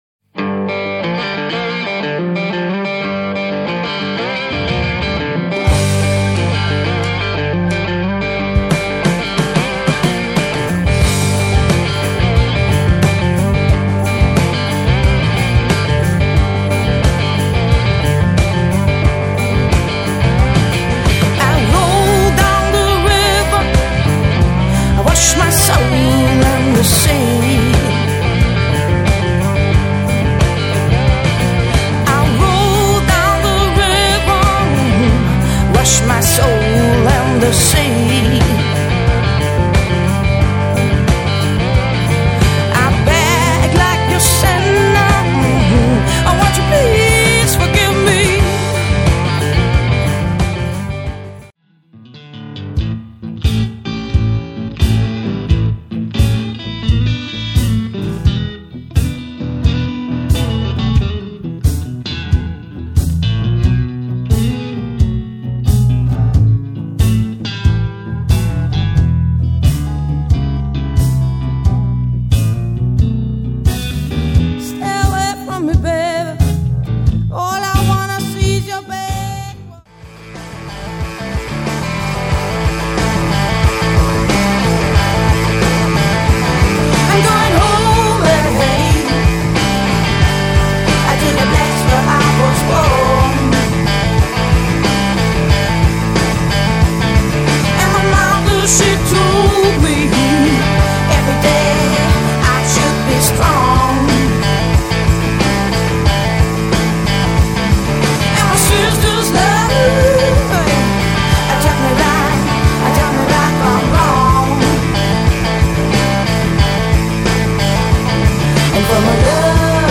vocal, acoustic guitar
hammond organ, wurlizer piano
trombone
saxophone
trumpet